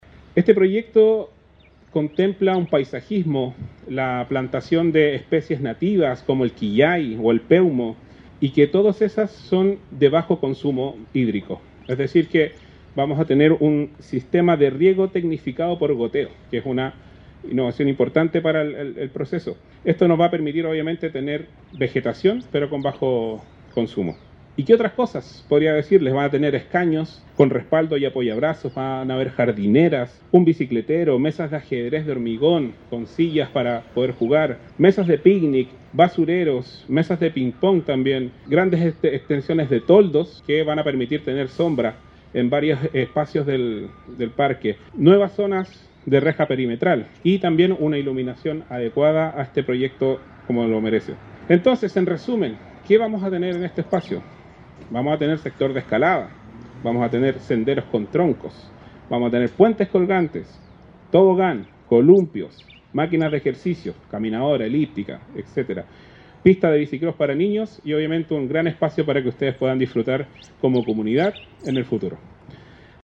DECLARACION-02-CONSTRUCTORA.mp3